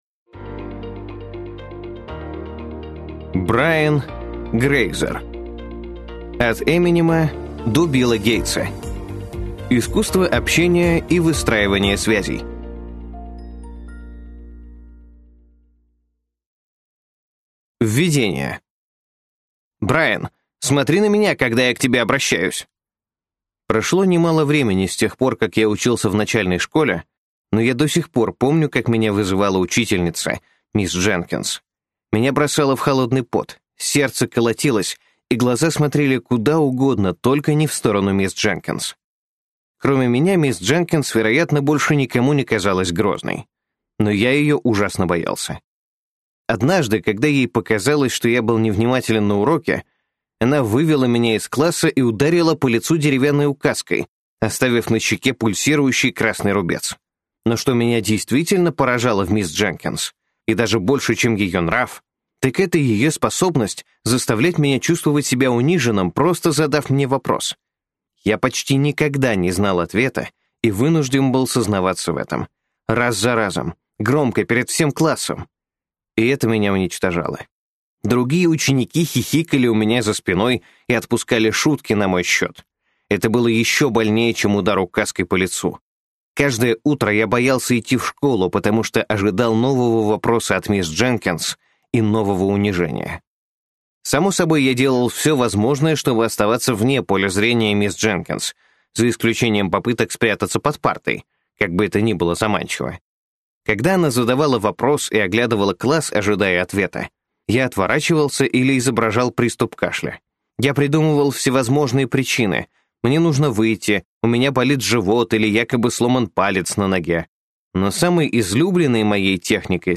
Аудиокнига От Эминема до Билла Гейтса. Искусство общения и выстраивания связей | Библиотека аудиокниг